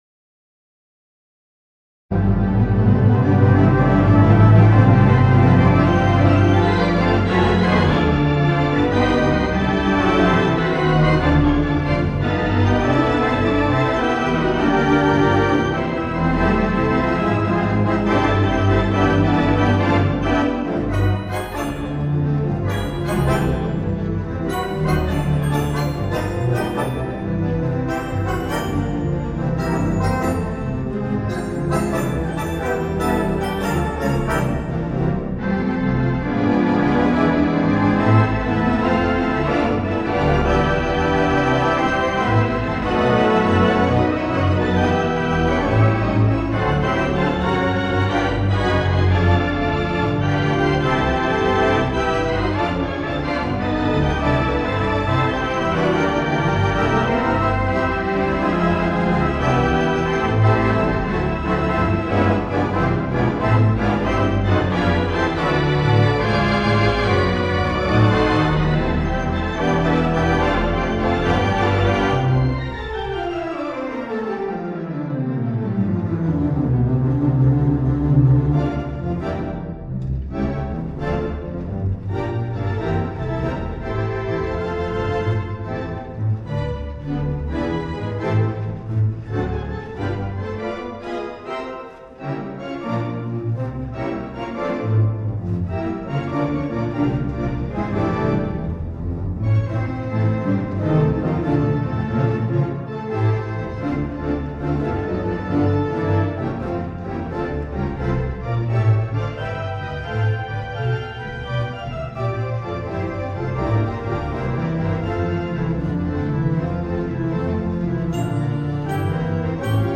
The Mighty WurliTzer on the stage